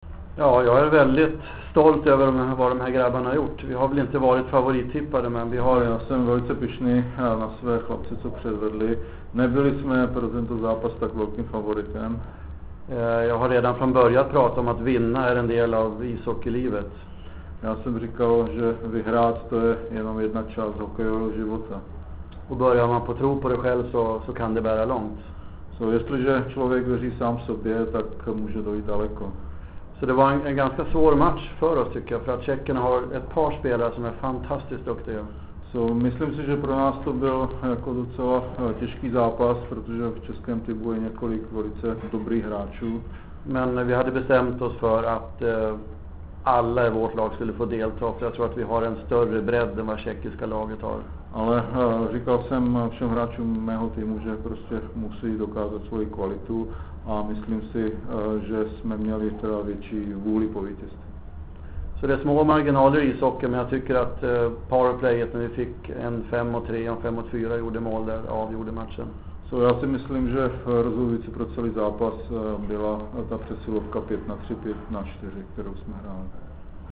Tiskov� konference - Miloslav Ho�ava (SWE - CZE) (mp3, 408 kB) P�ehr�t audio